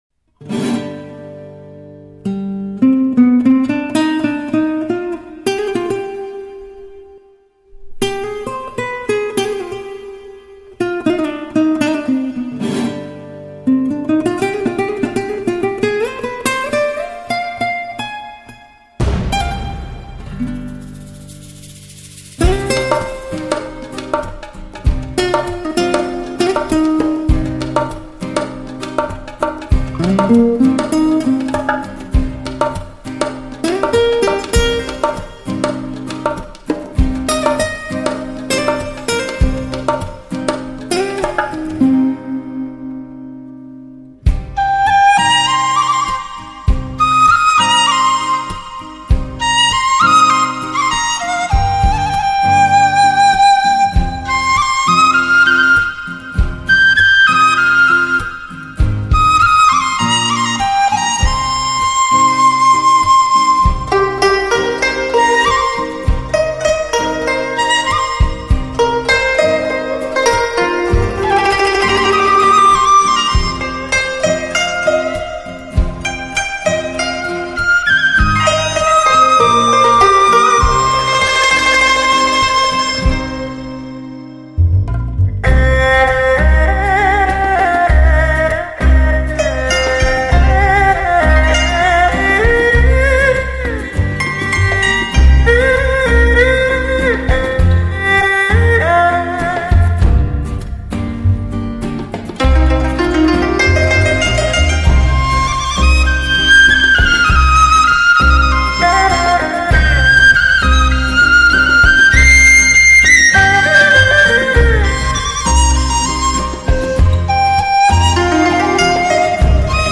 专辑格式：DTS-CD-5.1声道
藏笛悠长，涤荡心灵，似幻还真。